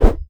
axe_swoosh1.wav